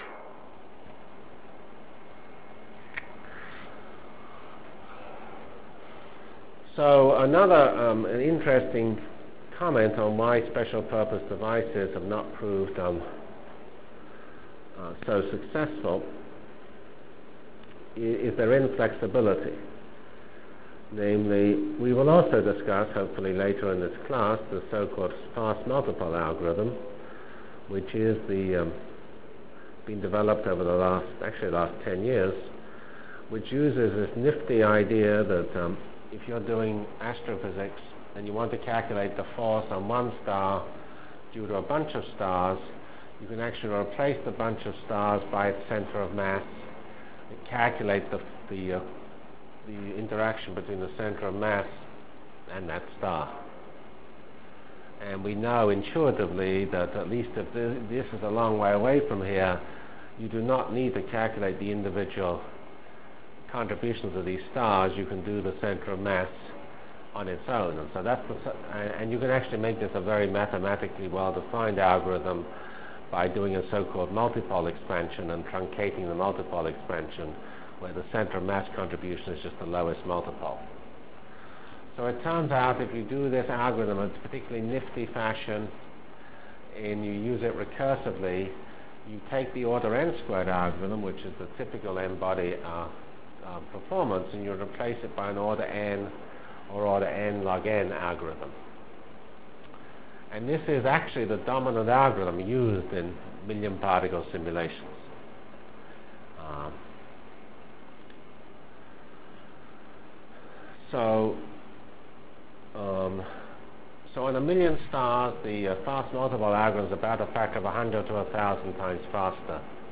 From CPS615-Lecture on Computer Architectures and Networks Delivered Lectures of CPS615 Basic Simulation Track for Computational Science -- 12 September 96.